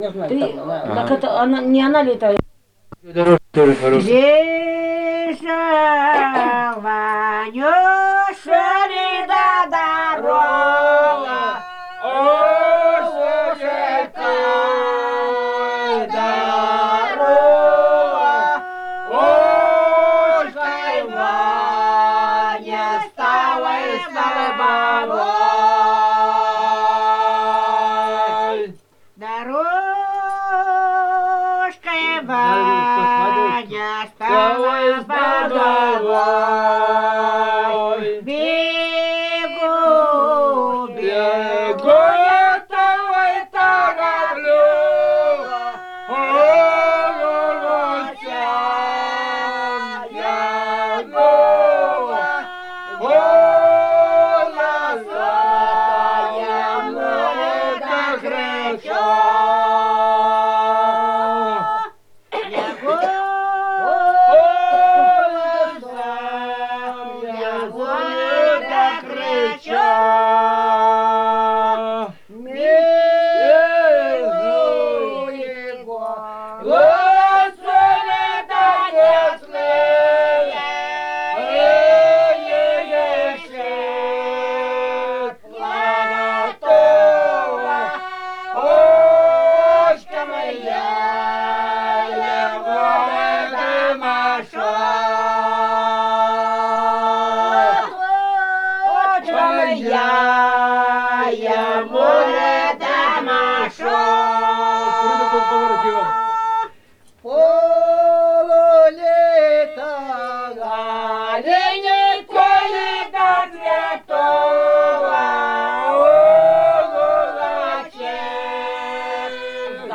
Старинные песни
"Бежал Ванюша ли да дорожкой" протяжная.
с. Кежма, 1993г.